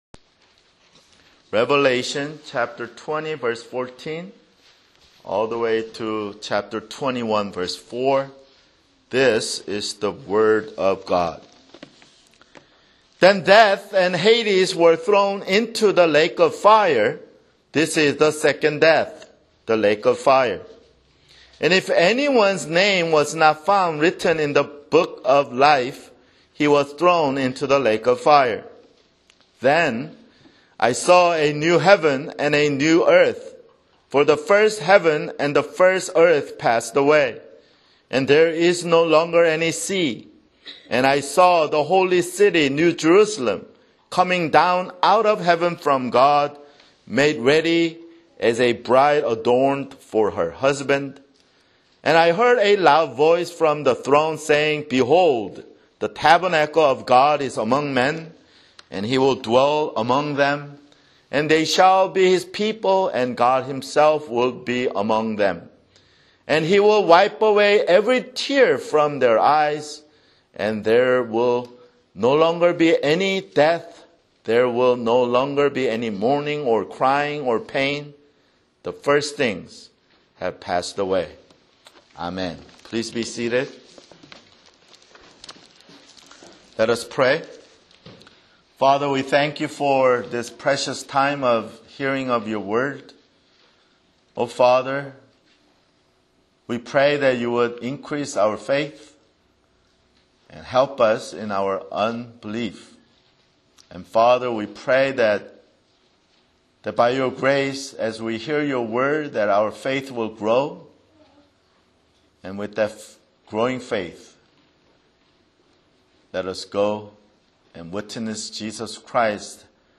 [Sermon] Revelation (81)